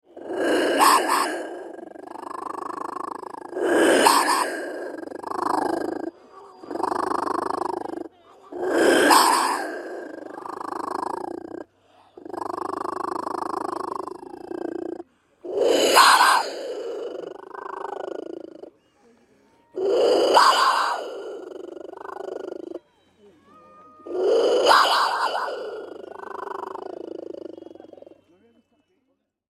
Jaguar head instrument at Teotihuacan Pyramids
Stereo 48kHz 24bit.